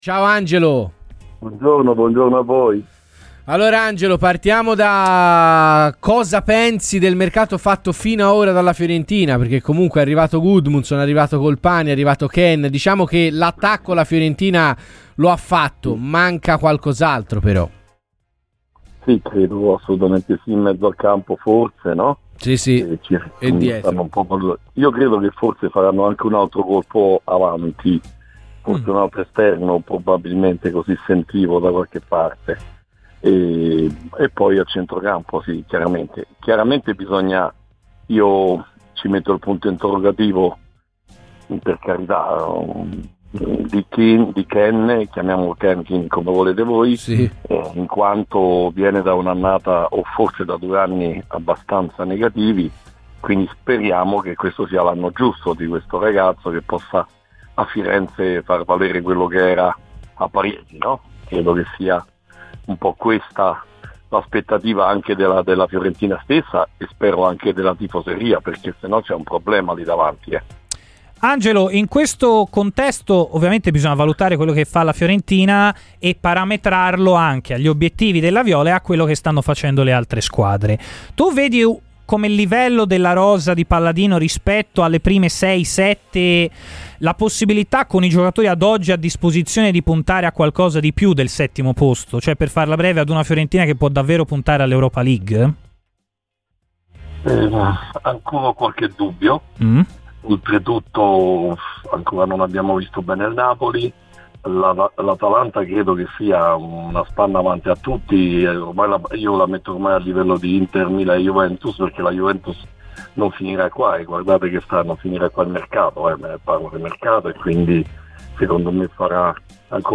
Ascolta il podcast per l'intervista integrale.